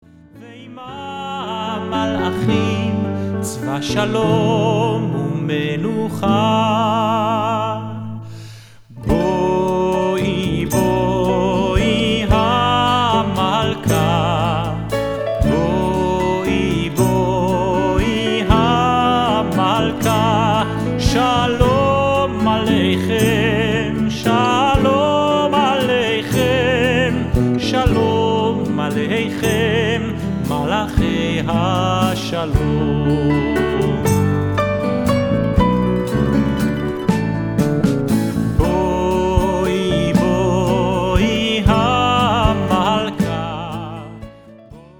a choir and instruments
organist